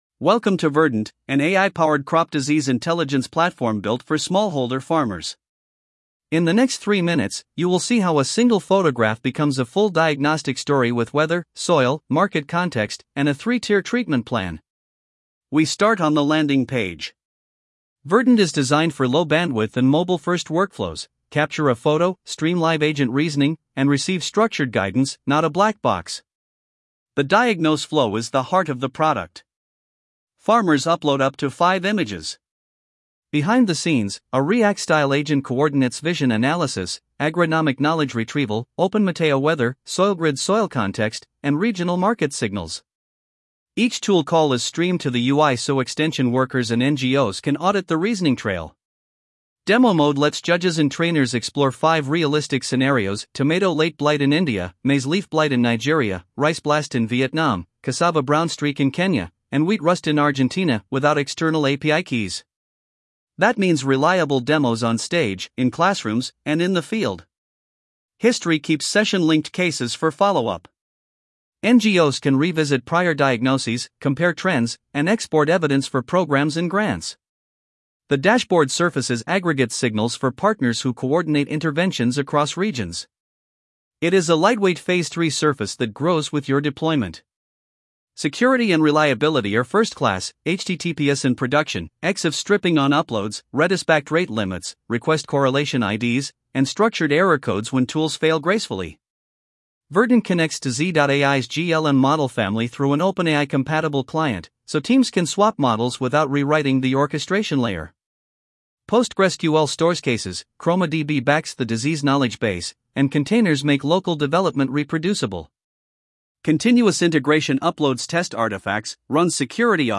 Narrated demo video
Slideshow of live UI captures with an AI voiceover (Microsoft Edge neural TTS).